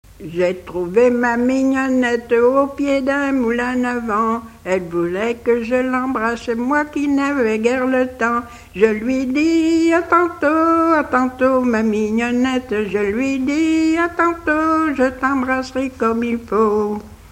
figure de quadrille
Couplets à danser
Pièce musicale inédite